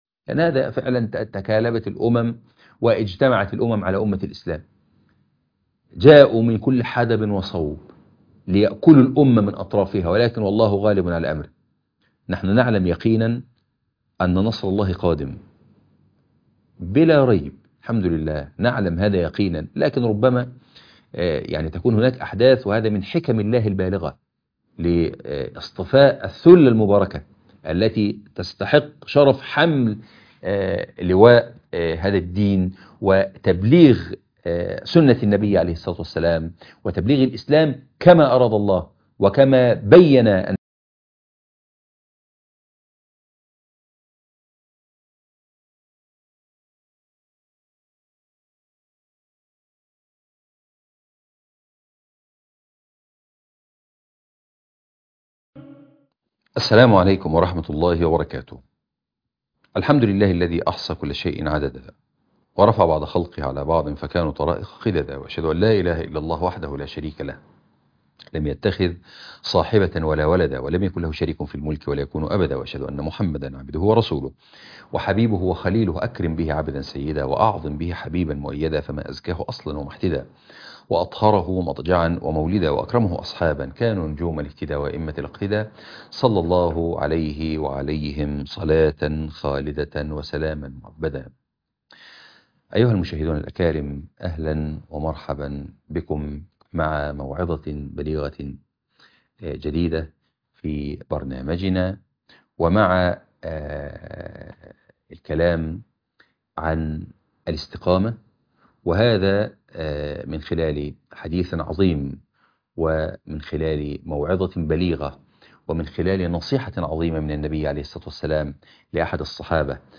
الاستقامة - موعظة بليغة ح 11